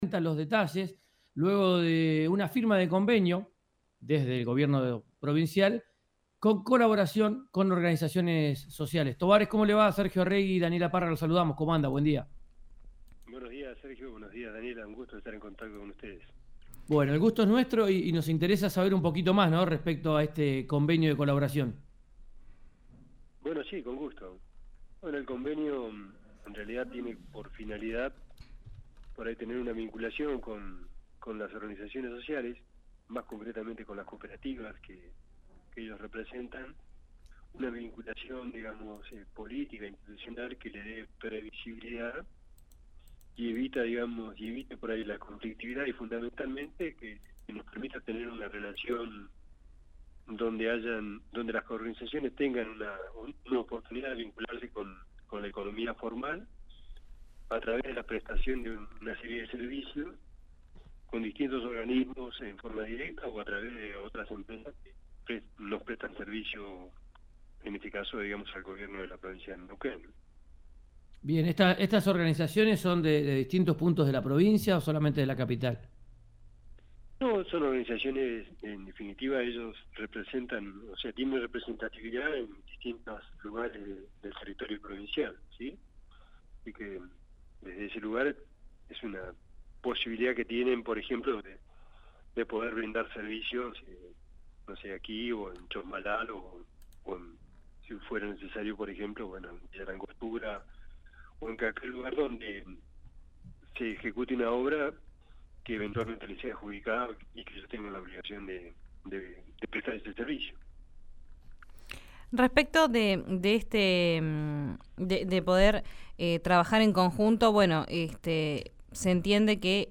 El ministro de Gobierno de Neuquén, Jorge Tobares, dialogó con RÍO NEGRO RADIO para explicar los detalles del convenio.
Escuchá a Jorge Tobares, ministro de Gobierno de Neuquén, en RÍO NEGRO RADIO: